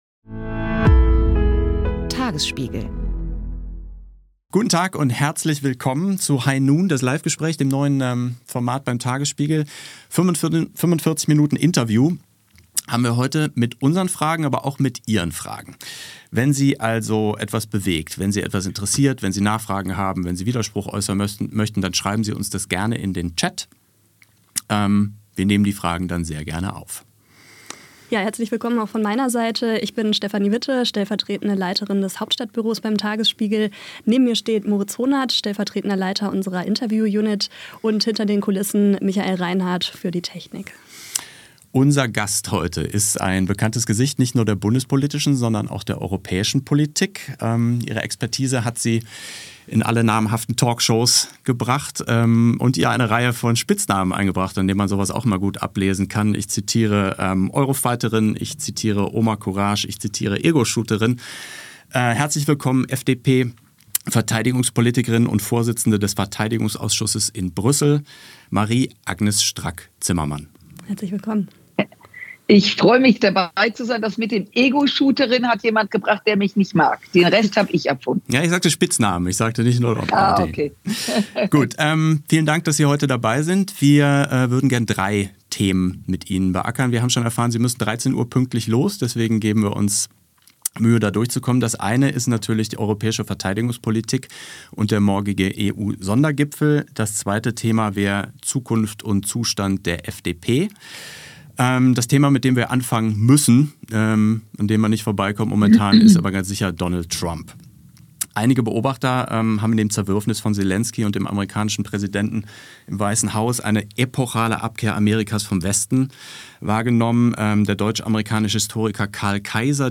Die Europaabgeordnete Marie-Agnes Strack-Zimmermann steht in unserer Reihe „High Noon“ Rede und Antwort.